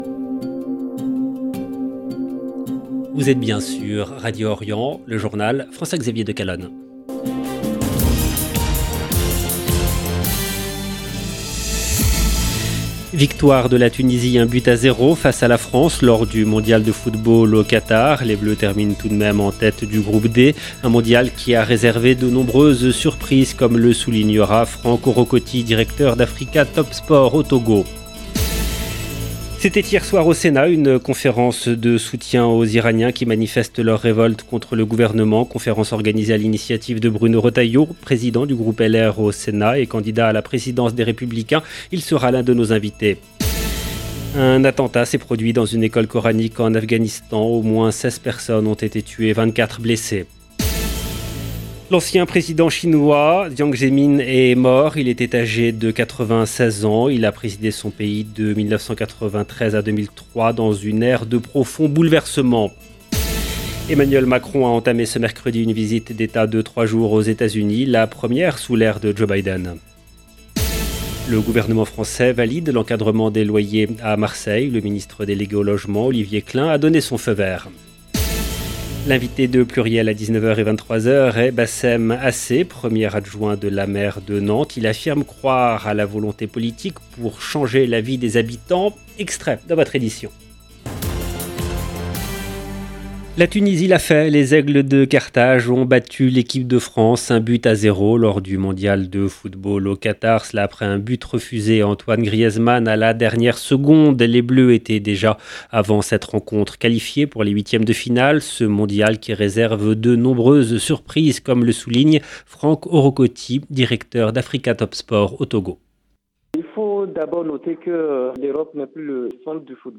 LE JOURNAL DU SOIR EN LANGUE FRANCAISE DU 30/11/22